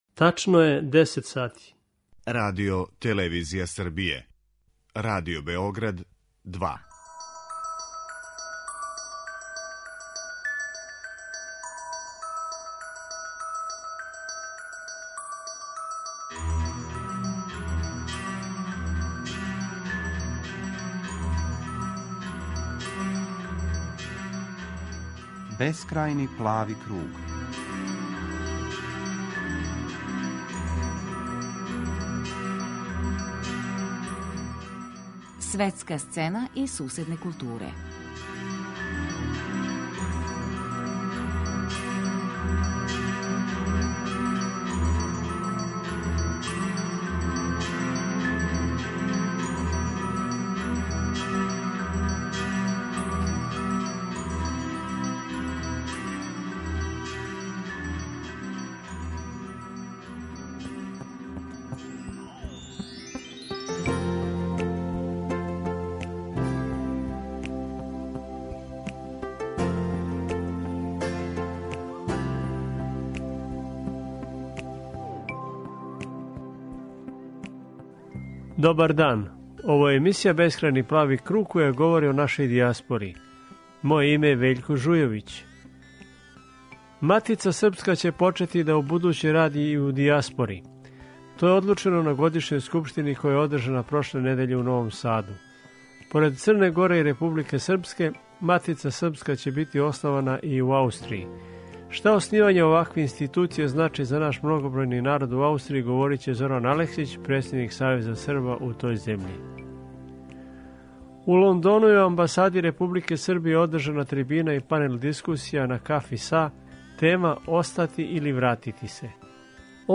У Лондону је у Амбасади Републике Србије одржана трибина и панел дискусија "На кафи са...", а тема је била "Остати или вратити се".